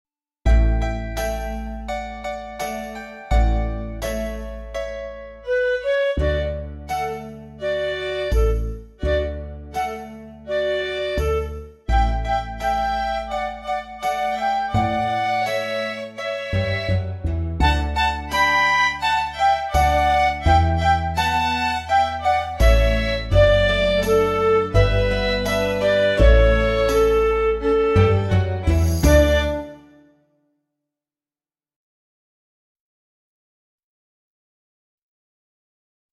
VS Friday Evening (backing track)
An episode by Simon Balle Music